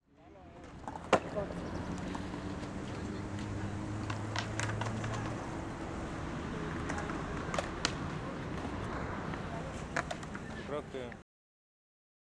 ambiente skate.wav
HOLOFONIKA FOLEY MEDELLIN 2013 es una apuesta desde lo sonoro para ayudar y fortalecer la escena Audiovisual de Medellin , creando así un banco de sonidos propios de la ciudad, que pueden ser utilizados para el diseño sonoro, arte o simplemente para tener una memoria sonora de una ciudad.